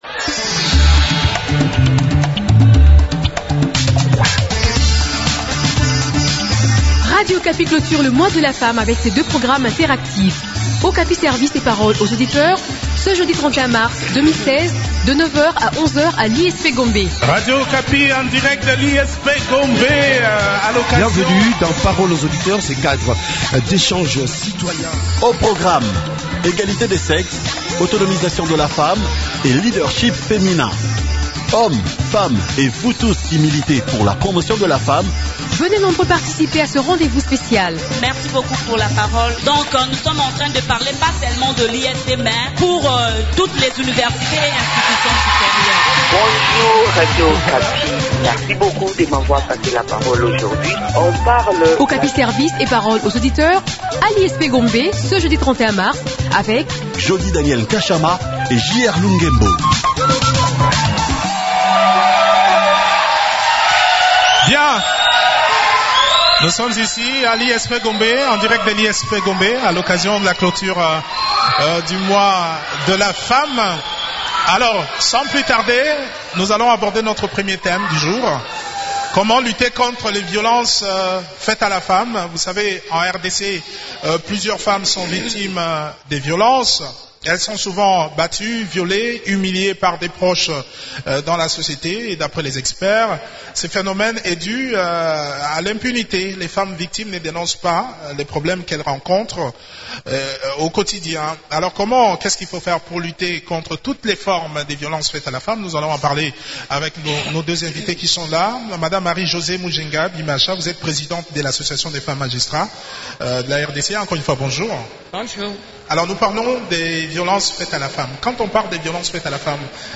Cette émission est produite en direct de l’Institut Supérieur Pédagogique de la Gombe (ISP/Gombe) à l’occasion de la clôture du mois de la femme.